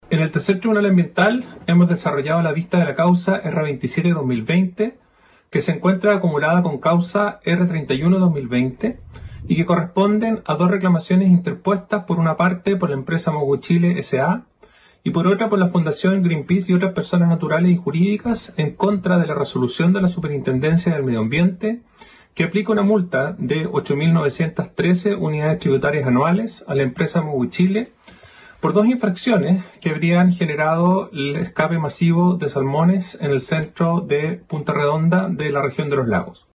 En la audiencia se escucharon los alegatos de la empresa Mowi S.A, Greenpeace y la Superintendencia de Medio Ambiente, cuyos argumentos se centraron en los sistemas de fondeo y alineación del Centro Punta Redonda que habrían causado el escape de 690.000 salmones. La reclamación se origina en la Resolución Exenta Nº1415 de la Superintendencia del Medio Ambiente, que resolvió el procedimiento administrativo sancionatorio contra Mowi S.A, así lo precisó Iván Hunter, Ministro del Tribunal Ambiental de Valdivia.